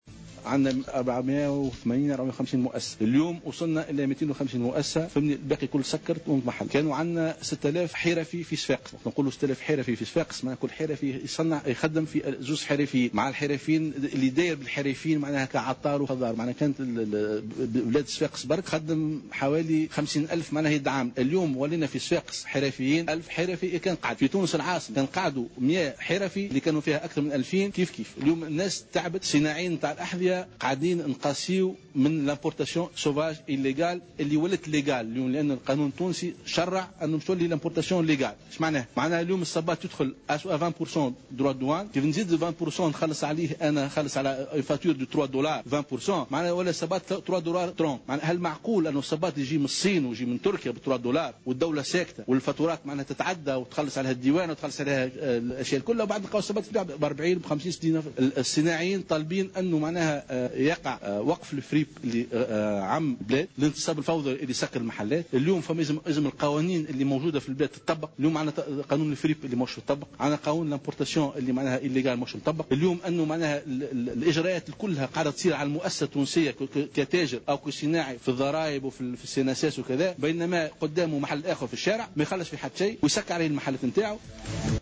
عقدت اليوم الجامعة الوطنية للجلود والأحذية ندوة صحفية لتسليط الضوء على الوضعية الصعبة التي يمر بها القطاع بسبب التجارة الموازية والسلع الموردة من الصين ومن تركيا.